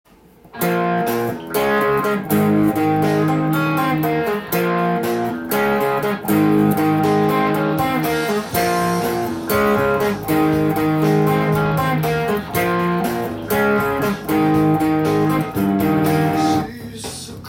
音源にあわせて譜面通り弾いてみました
イントロが印象深いエレキギター　オーバードライブサウンドですが
リズムは、小節をまたぐシンコペーションがありますが